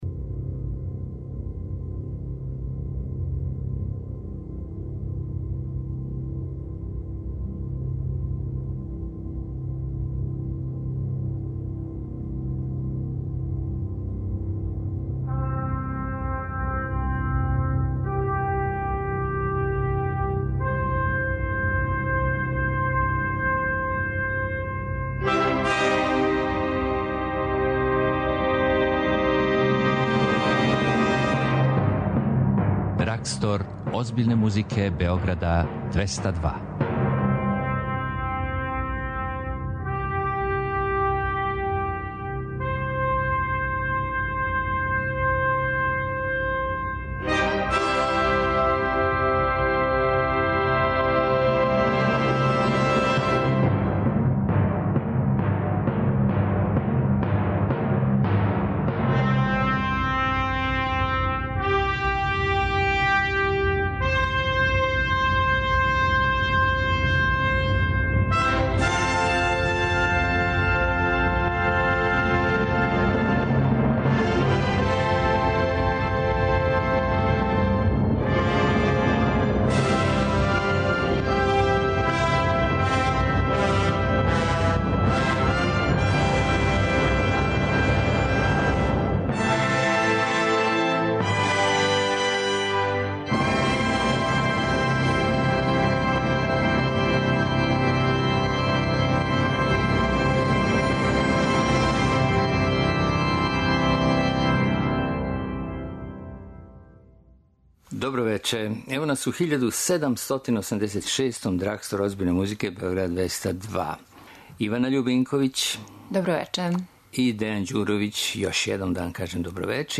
класичну музику